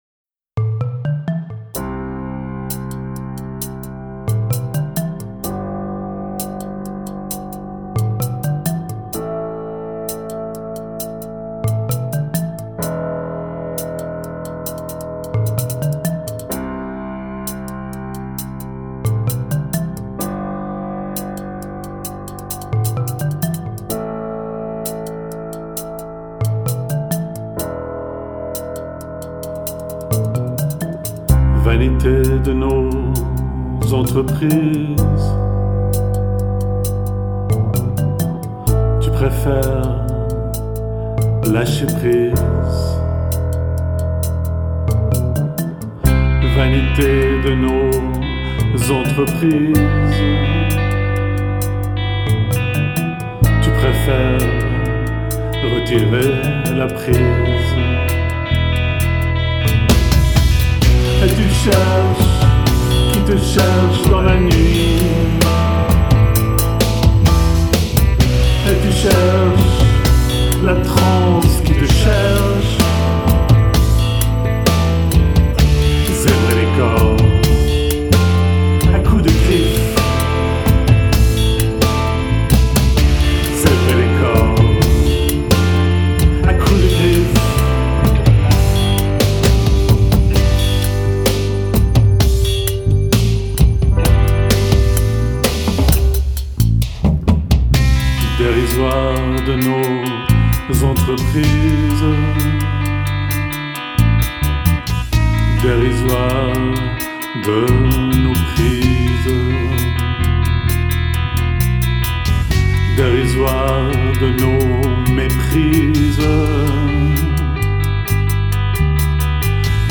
guitare, basse, clavier
Studio des Anges, Lausanne